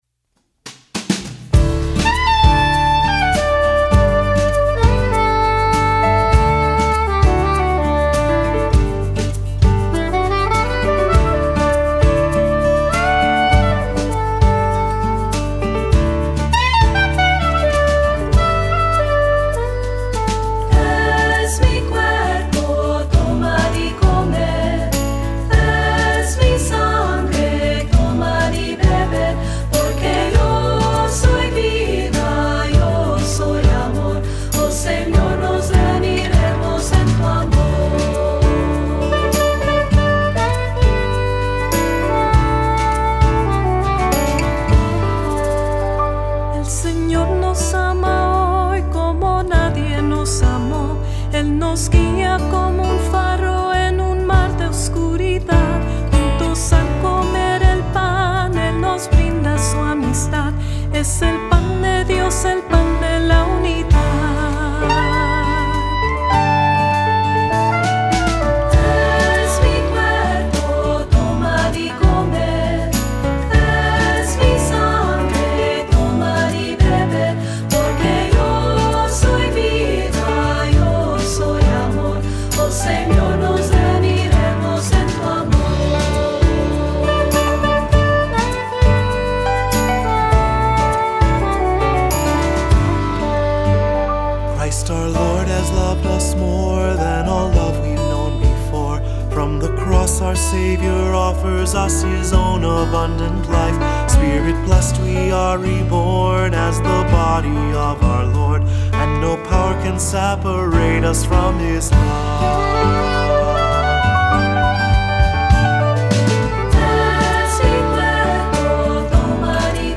Voicing: SAB: Cantor; Assembly